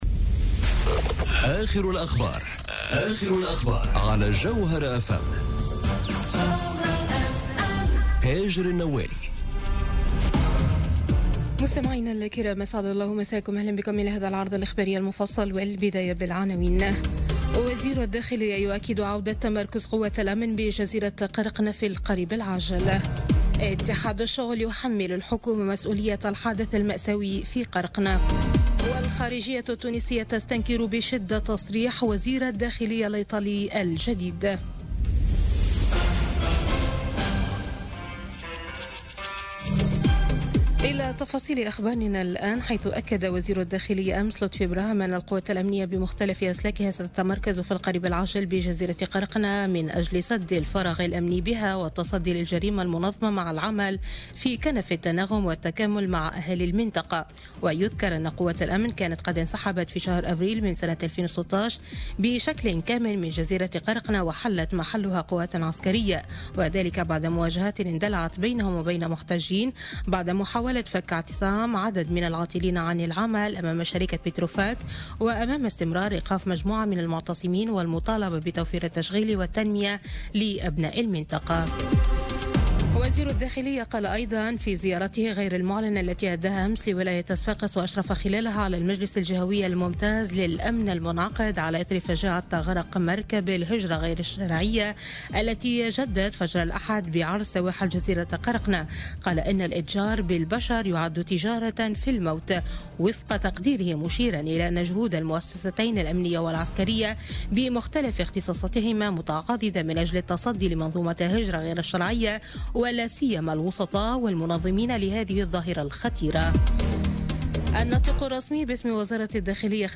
نشرة أخبار منتصف الليل ليوم الثلاثاء 5 جوان 2018